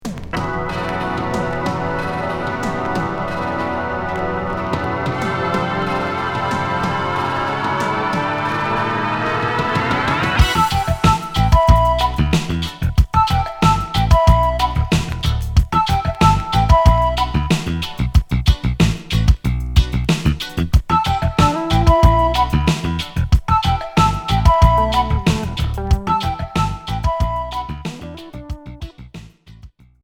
Reggae fusion Troisième 45t retour à l'accueil